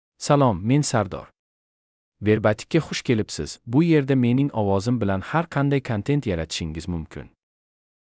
Sardor — Male Uzbek (Latin, Uzbekistan) AI Voice | TTS, Voice Cloning & Video | Verbatik AI
Sardor is a male AI voice for Uzbek (Latin, Uzbekistan).
Voice sample
Listen to Sardor's male Uzbek voice.
Sardor delivers clear pronunciation with authentic Latin, Uzbekistan Uzbek intonation, making your content sound professionally produced.